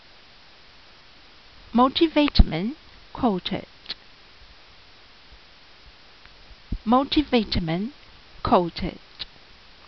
Pronunciation[edit]
Multivitamin_coated.mp3